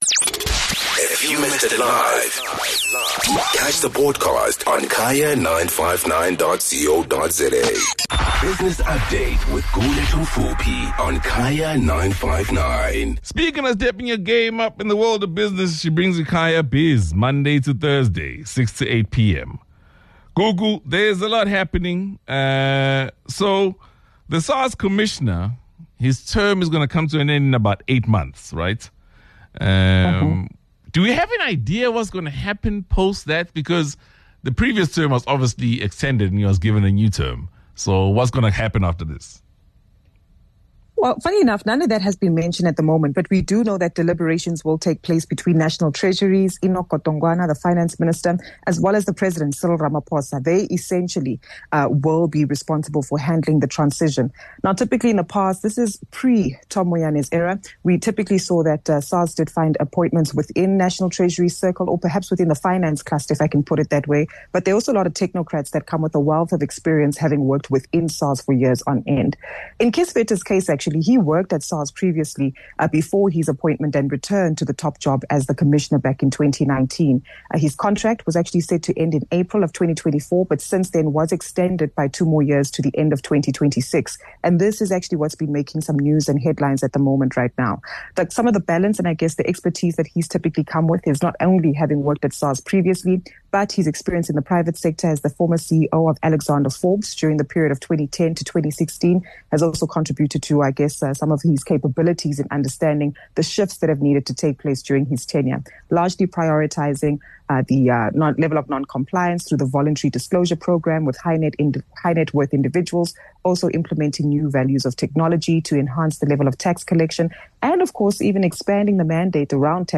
Business Update